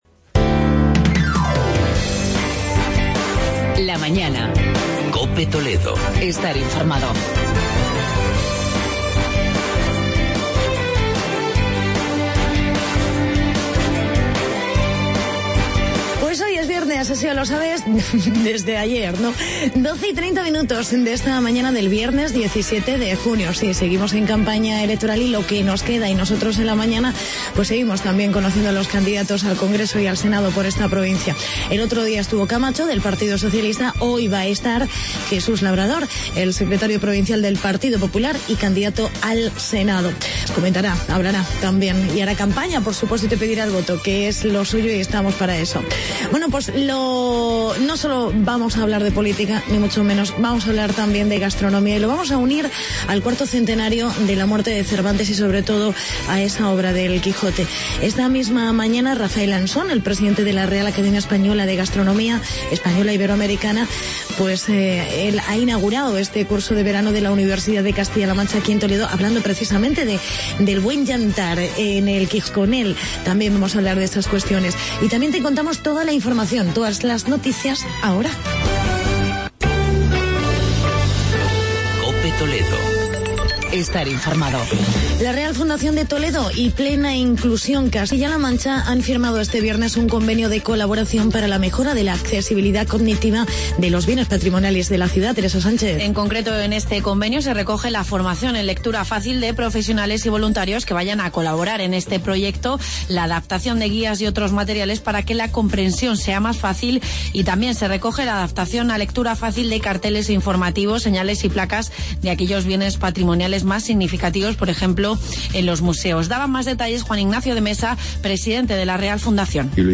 Entrevista con el candidato popular al Senado por la provincia de Toledo, Jesús Labrador.